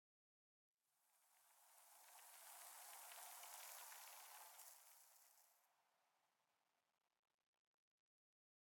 Minecraft Version Minecraft Version snapshot Latest Release | Latest Snapshot snapshot / assets / minecraft / sounds / block / sand / sand7.ogg Compare With Compare With Latest Release | Latest Snapshot
sand7.ogg